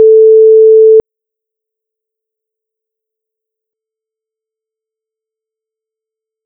Attached is a very low level 440Hz sine tone (pure tone) that has been converted from 32 bit float to 16 bit, first with dither and then without dither.
And here is the same thing, but at its natural volume. In this sample it begins with a 0 dB (loud) tone so that you can set your playback system to a “normal” level.
At a “normal” playback level we are talking about quite subtle differences.